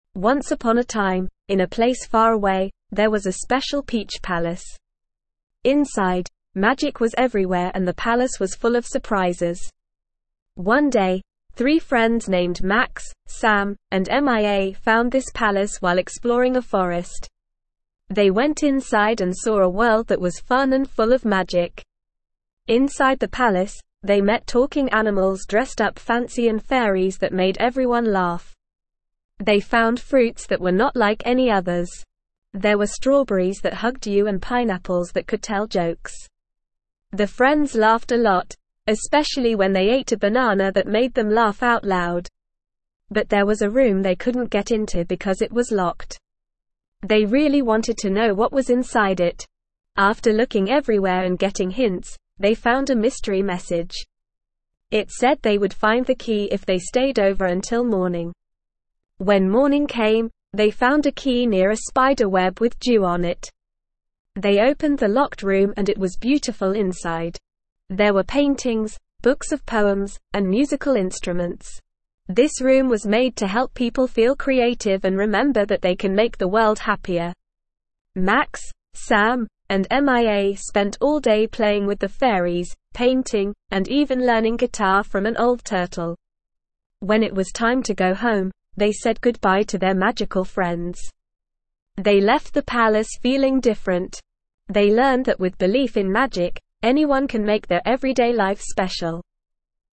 Normal
ESL-Short-Stories-for-Kids-Lower-Intermediate-NORMAL-Reading-The-Peculiar-Peach-Palace.mp3